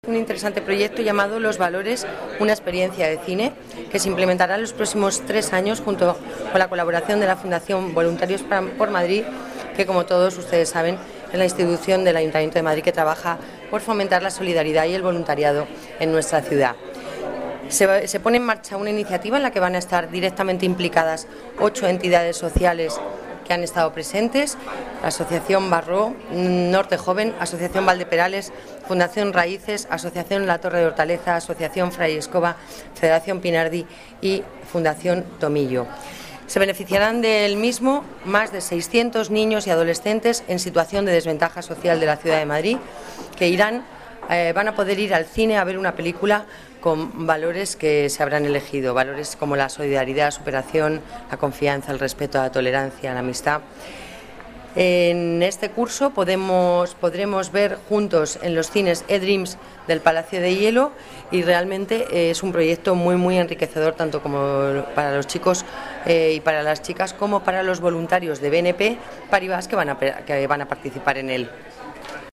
Nueva ventana:Declaraciones delegada Familia y Servicios Sociales, Lola Navarro: proyecto Los valores: una experiencia de cine